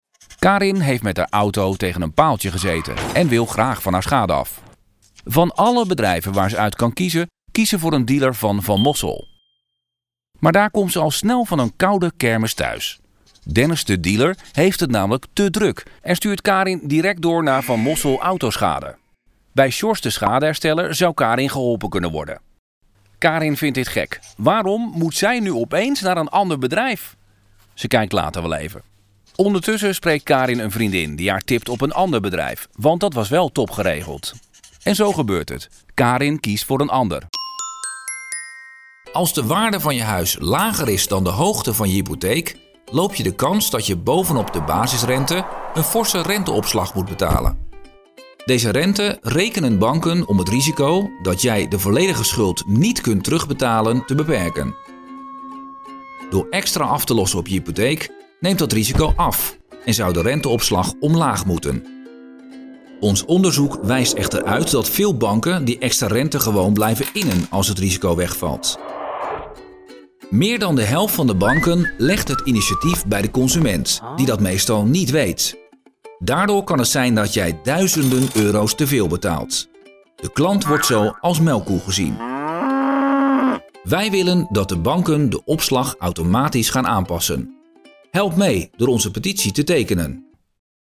Natuurlijk, Opvallend, Toegankelijk, Vertrouwd, Vriendelijk
Explainer